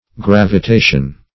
Gravitation \Grav"i*ta"tion\, n. [Cf. F. gravitation.